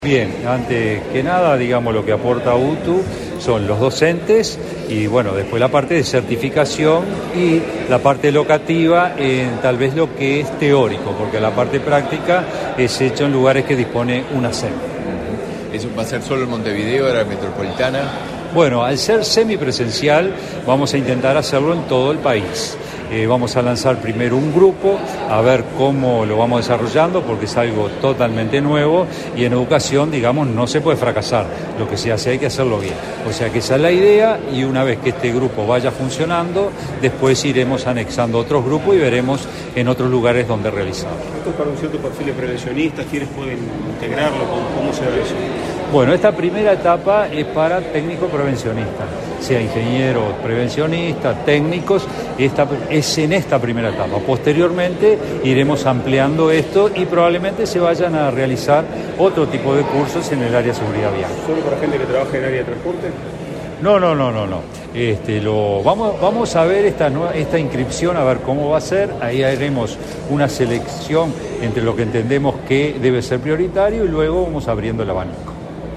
Declaraciones a la prensa del director general de UTU, Juan Pereyra
Tras participar en el lanzamiento del curso de especialización en seguridad vial organizado por la UTU y la Unidad Nacional de Seguridad Vial (Unasev)